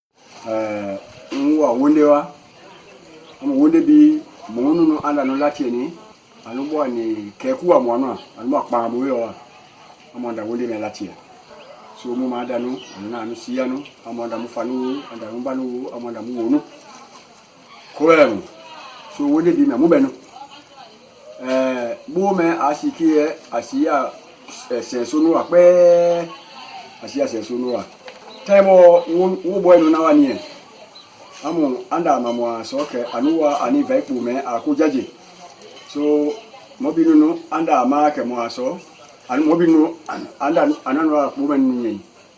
The sound quality was poor, but I’ve found a way to improve it now.
14 June 2017 at 12:11 am With the tones, syllabic [n̩], labial-velar [k͡p], and fairly-average-sounding vowel system, I’m guessing it’s a language of West Africa.